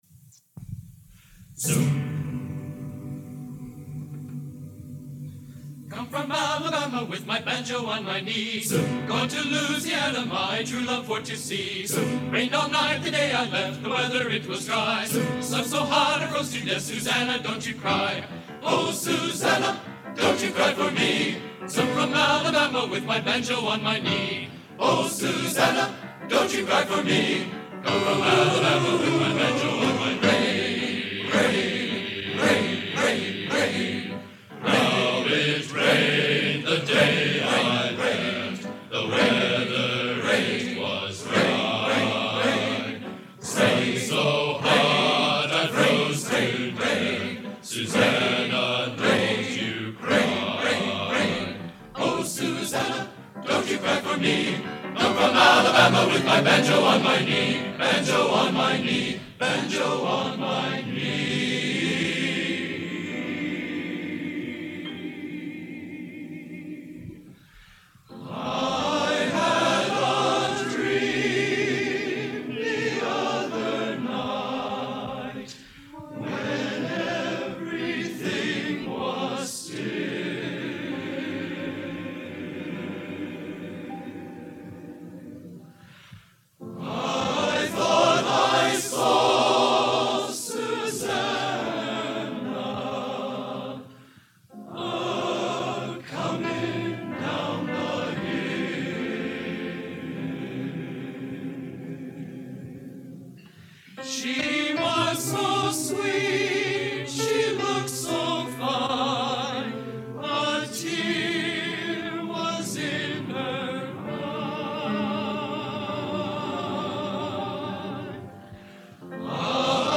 Genre: Traditional | Type: End of Season